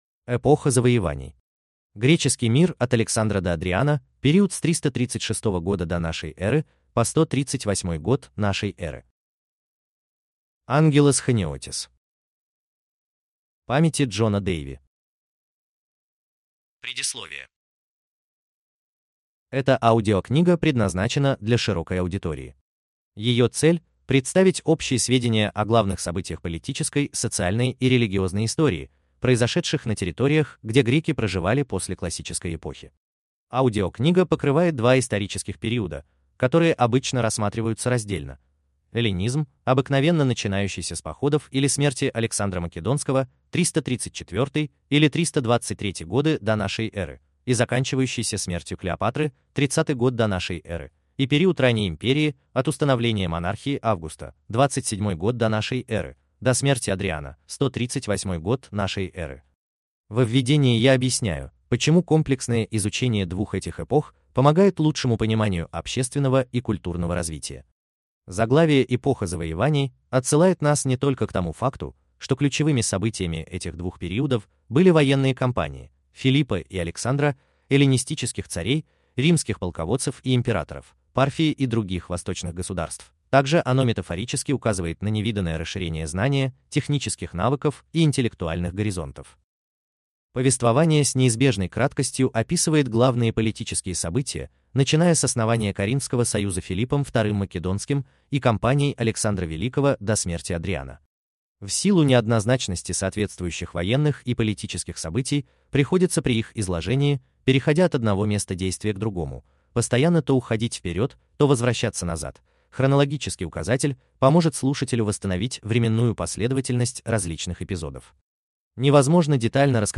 Аудиокнига Эпоха завоеваний | Библиотека аудиокниг